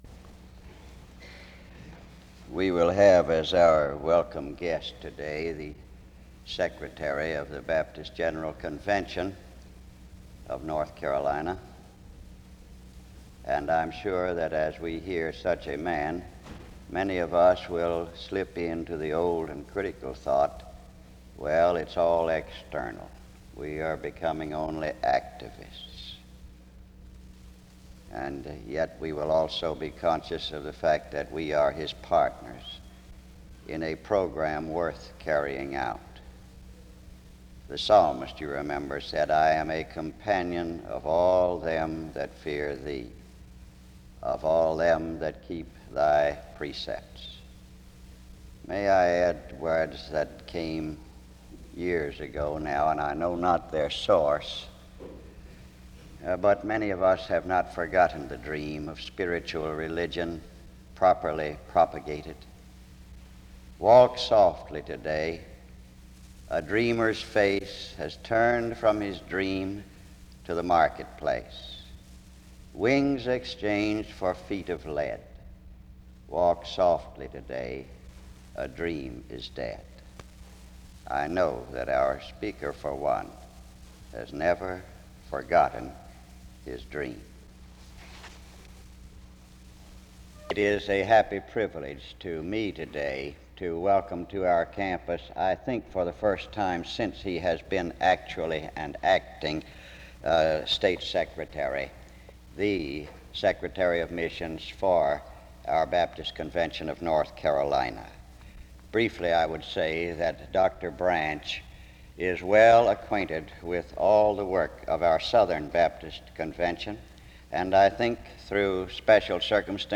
The three lessons are maintaining a wholesome self-scrutiny, the need for developing lay people, and there is tremendous value in the relationship to that denomination of which you are a part (3:31-19:01). The service concludes with a hymn (19:02-end).
SEBTS Chapel and Special Event Recordings SEBTS Chapel and Special Event Recordings